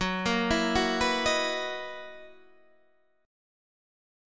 Gb13 chord